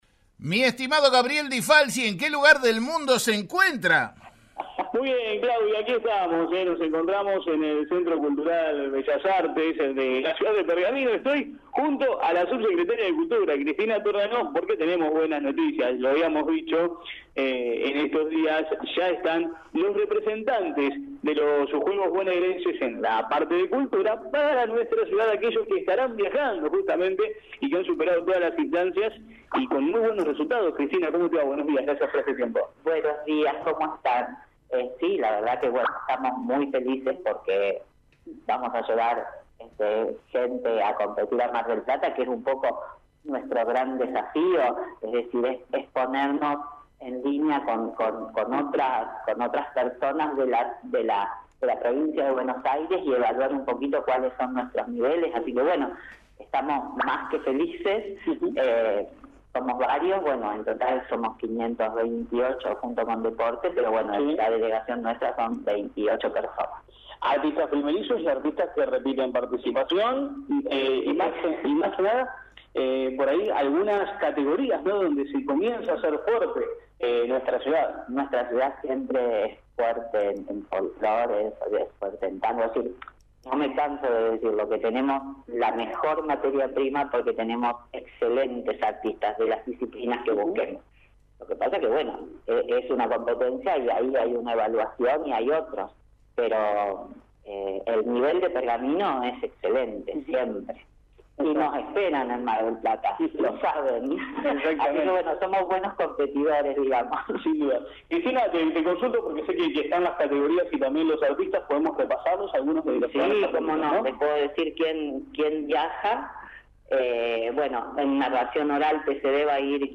Cristina Torrano, subsecretaria de Cultura del Partido de Pergamino, habló desde del móvil de La Mañana de la Radio, y repasó los clasificados de Cultura para los próximos Bonaerenses.